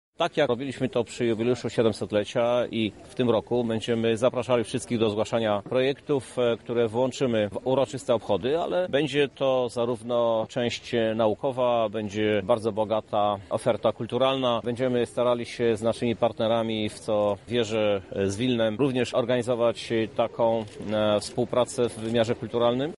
O tym jak Lublin będzie przygotowany na jubileusz mówi prezydent Krzysztof Żuk